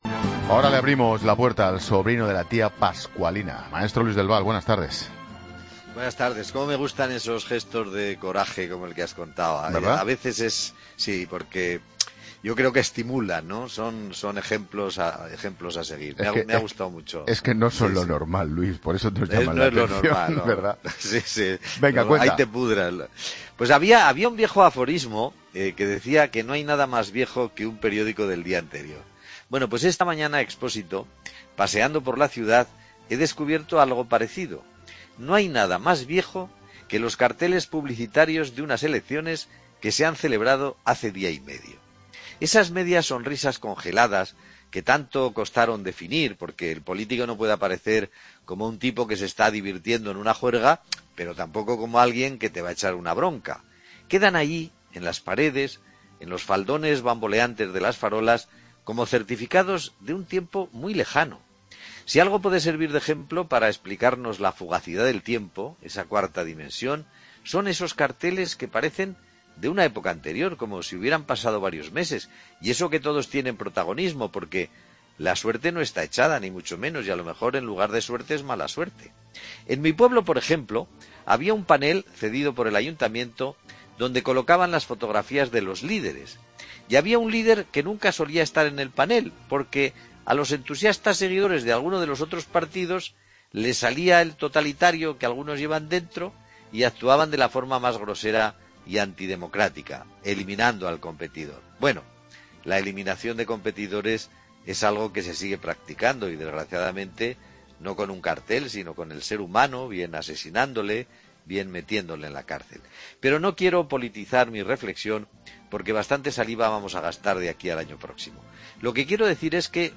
" dedica un comentario a los restos de propaganda política que quedan visibles tras las elecciones.